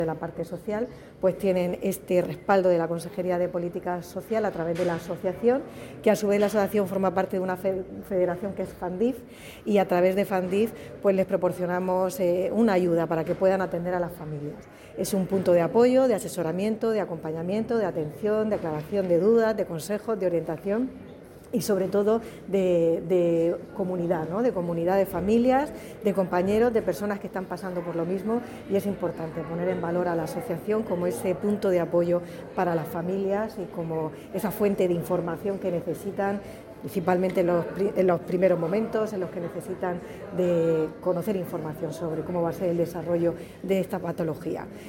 Declaraciones de la consejera de Política Social, Familias e Igualdad sobre el apoyo de la Consejería de Salud a la Asociación Murciana de Fibrosis Quística [mp3]
El consejero de Salud y la consejera de Política Social, Familias e Igualdad han participado en el acto por el Día Mundial de la Fibrosis Quística